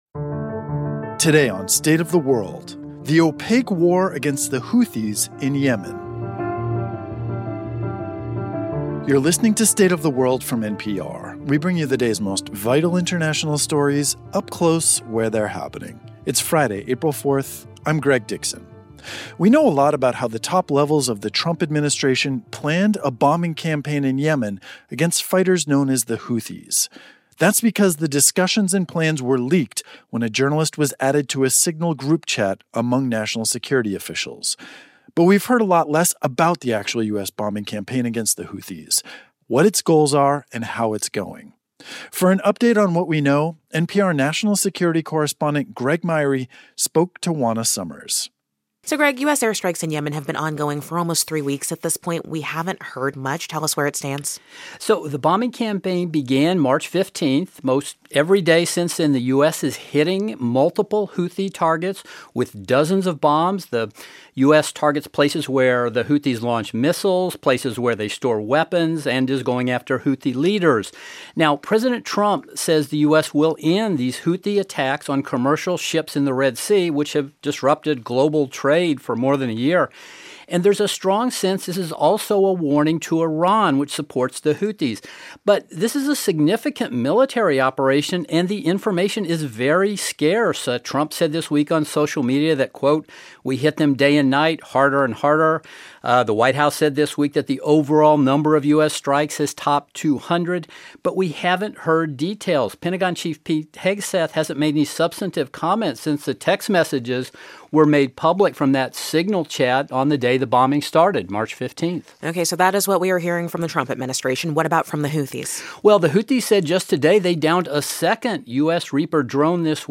That bombing campaign is ongoing and we haven't heard much about how it is going or its goals. Our national security correspondent tells us about what the U.S. military is doing in Yemen and how we're getting more information about that from the Houthis than from the Pentagon.